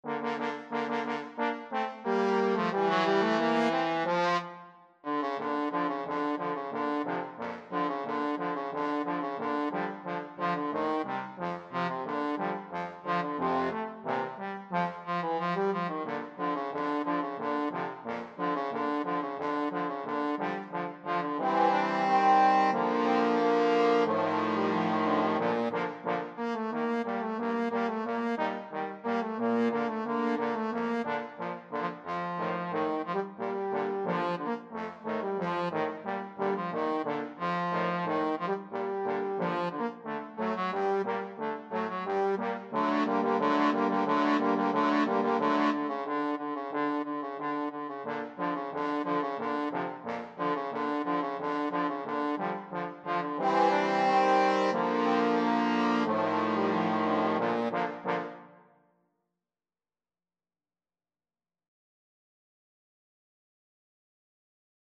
Free Sheet music for Trombone Quartet
4/4 (View more 4/4 Music)
Bb major (Sounding Pitch) (View more Bb major Music for Trombone Quartet )
Allegro =180 (View more music marked Allegro)
Classical (View more Classical Trombone Quartet Music)
radetsky_march_4TBNE.mp3